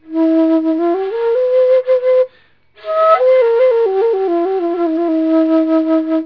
Flutes and Whistles
Hear a pentatonic curved ceramic flute